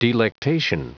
Prononciation du mot delectation en anglais (fichier audio)
Prononciation du mot : delectation